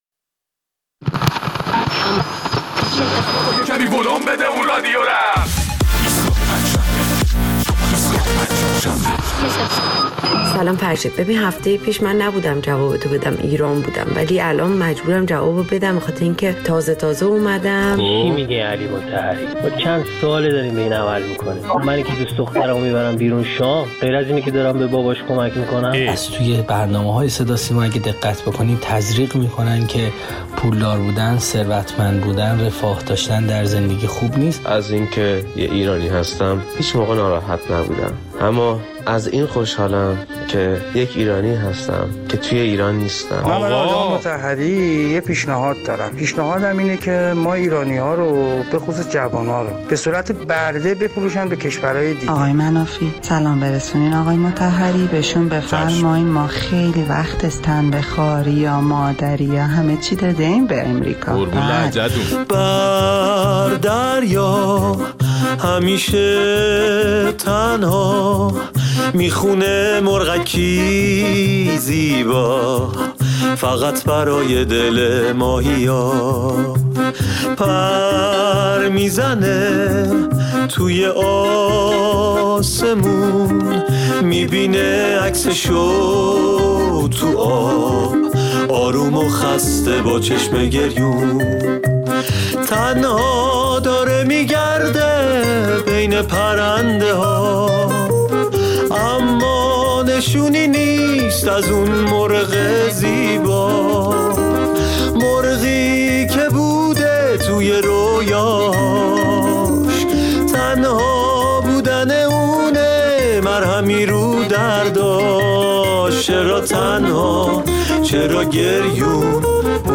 در این برنامه ادامه واکنش‌های مخاطبان ایستگاه فردا را به پیشنهاد علی مطهری در مورد کمک خانواده‌های برخوردار به خانواده‌های نیازمند برای گذراندن دوران تحریم می‌شنویم.